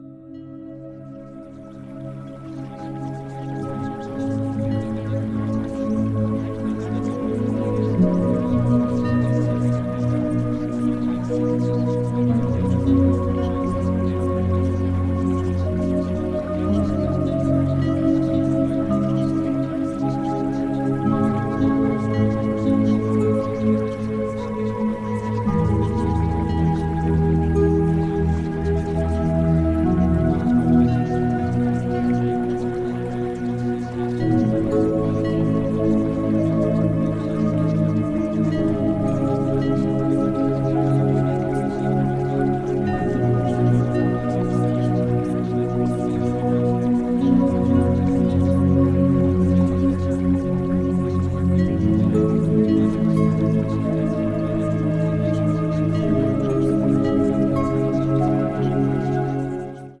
Here are samples of the 4 music mixes.